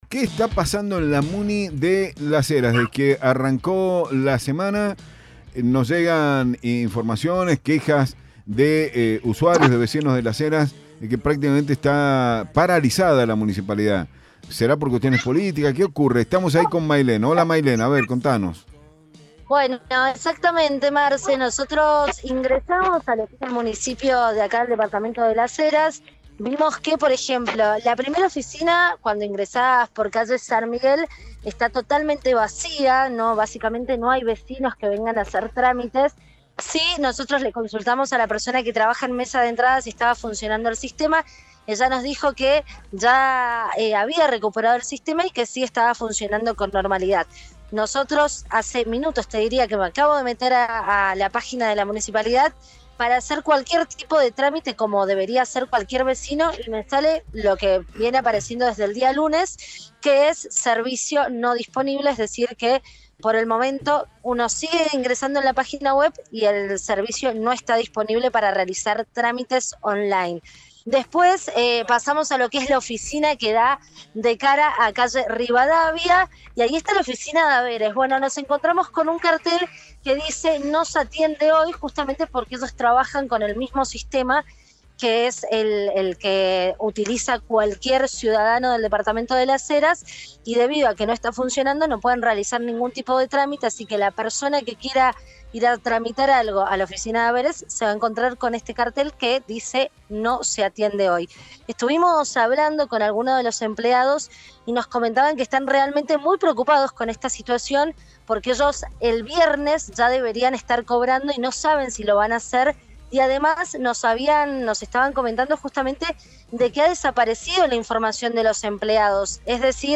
LVDiez - Radio de Cuyo - Móvil de LVDiez desde Municipalidad de Las Heras